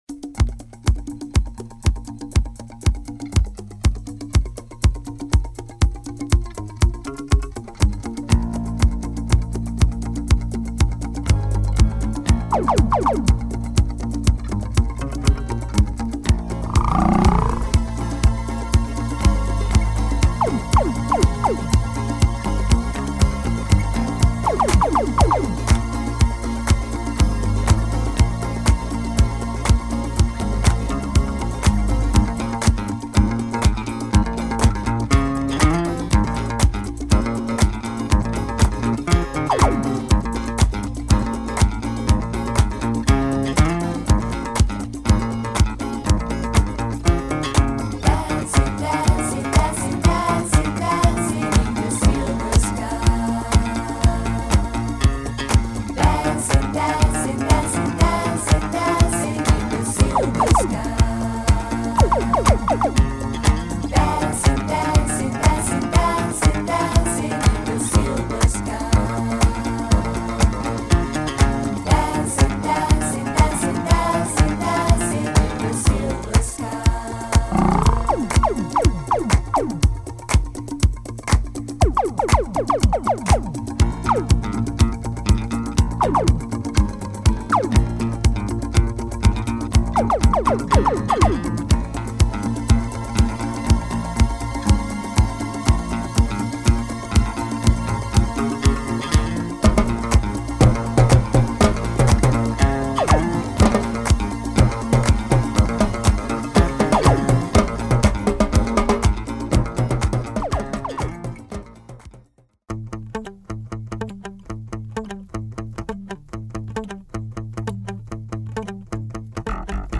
Killer groove and disco from Venezuela !